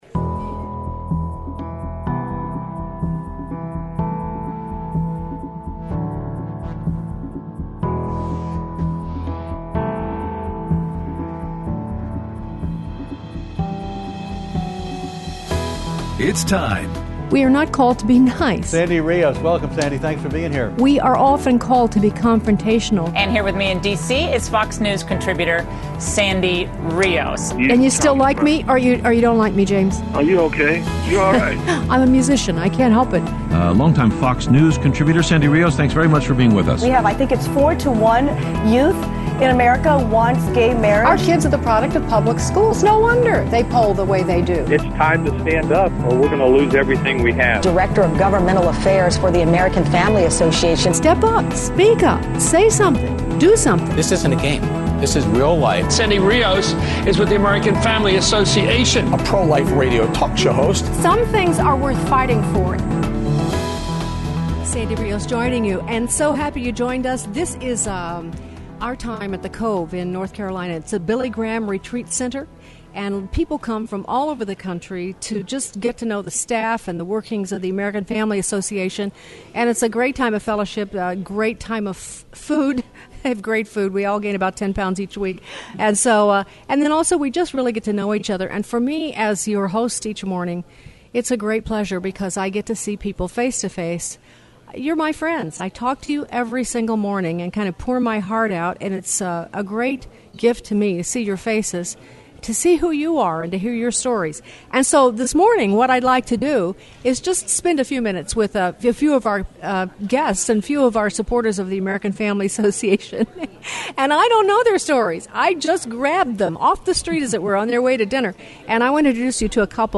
Interview
at the AFA Retreat at The Cove